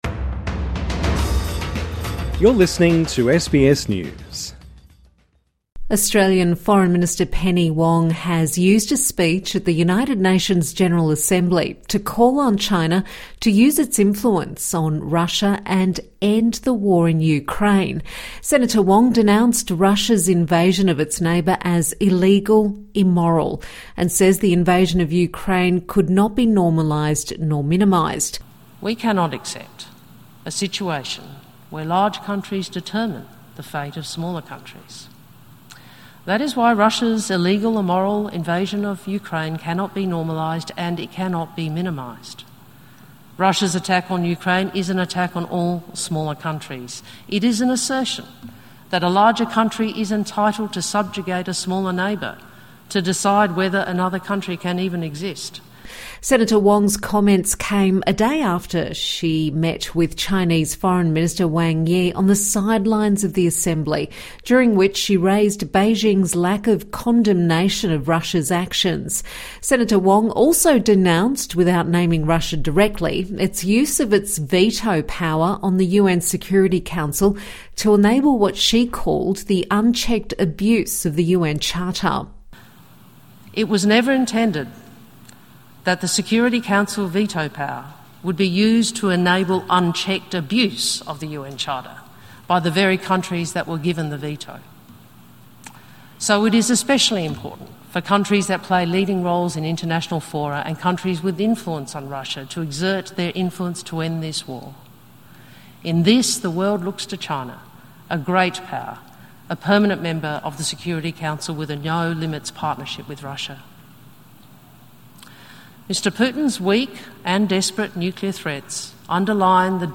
Australian foreign minister Penny Wong is calling on China to help end the Ukraine war. Ms Wong says the world is looking to China to help end that war, as she addressed the United Nations General Assembly in New York.